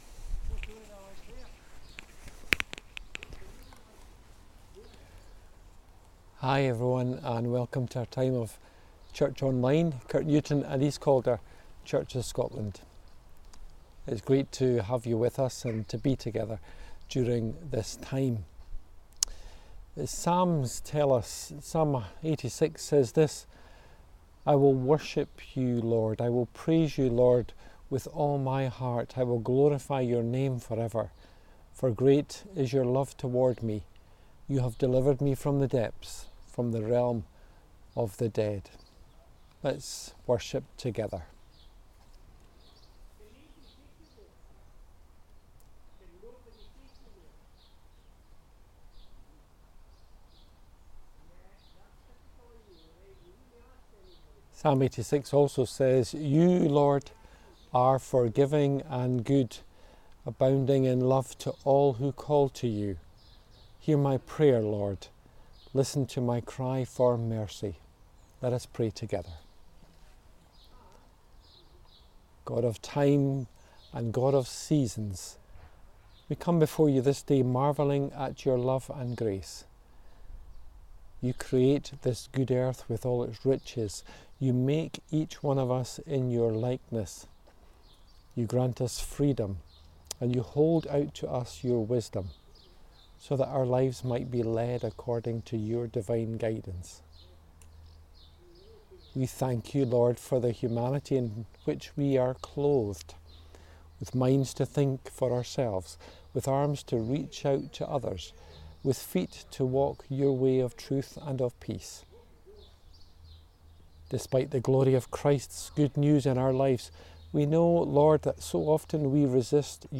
KNEC Sermon Podcasts